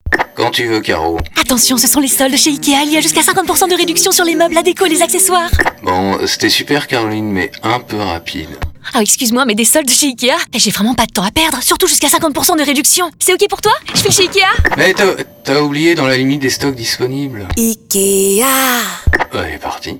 Voix Officielle IKEA Publicité Radio « IKEA Soldes »
Drôle, Dynamique
Punchy, radio, Rapide, Souriant, voix, Voix off